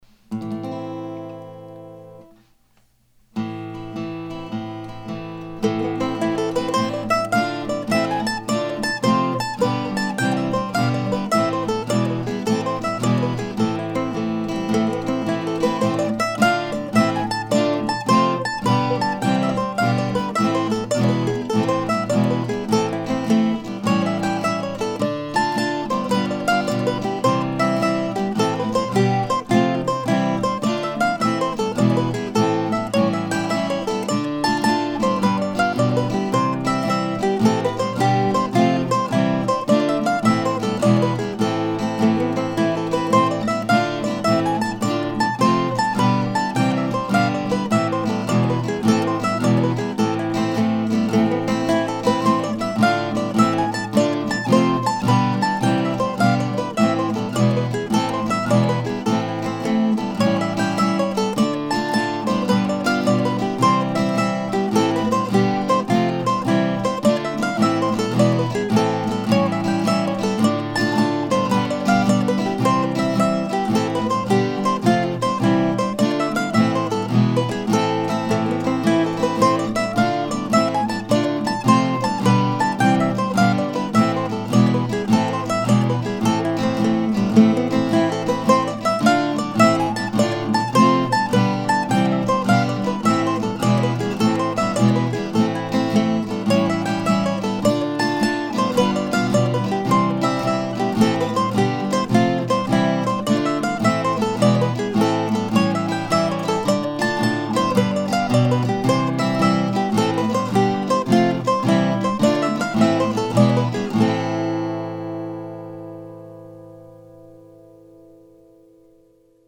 Since I would literally be returning to Dream Acres for the dance, I gave this jig that title.
As I was recording it I started thinking "this is almost more of a tarantella than a jig."